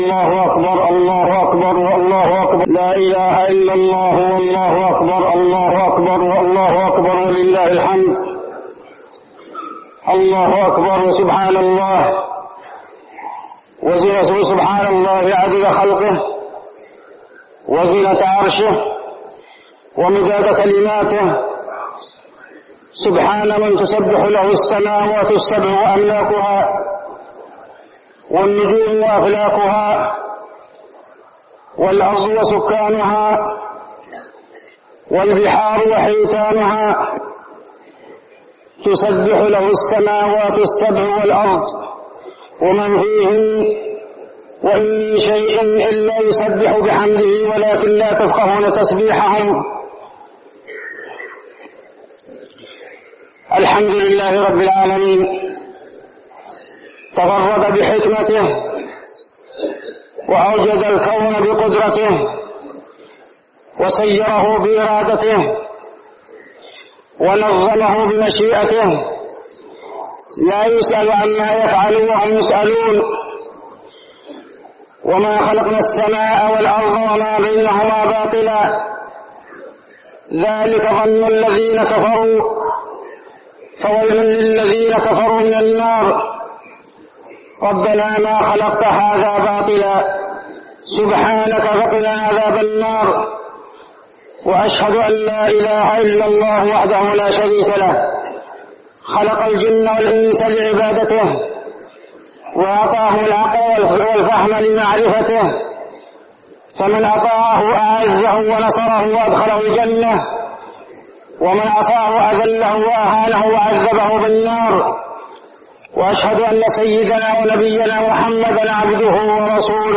خطبة عيد الفطر - المدينة - الشيخ عبدالله الزاحم
تاريخ النشر ١ شوال ١٤١٤ هـ المكان: المسجد النبوي الشيخ: عبدالله بن محمد الزاحم عبدالله بن محمد الزاحم خطبة عيد الفطر - المدينة - الشيخ عبدالله الزاحم